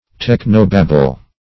Technobabble \Tech"no*bab`ble\, n.